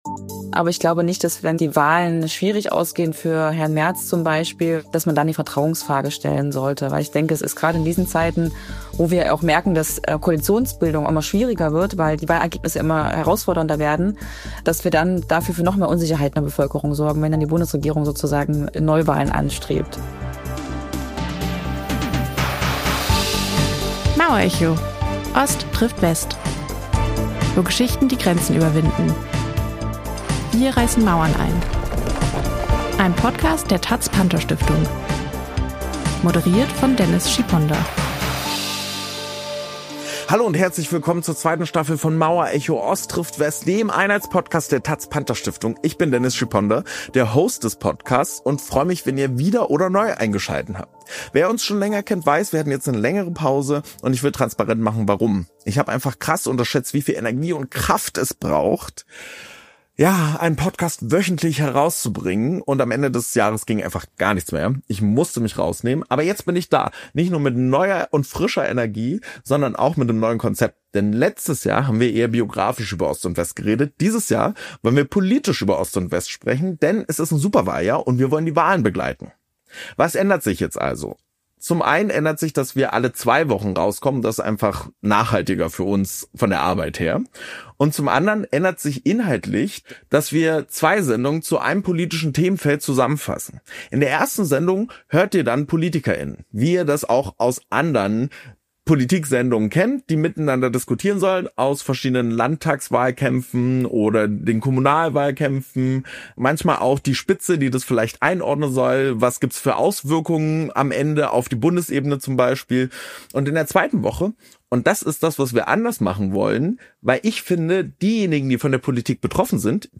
Zu Gast ist Elisabeth Kaiser, SPD- Bundestagsabgeordnete und Beauftragte der Bundesregierung für Ostdeutschland.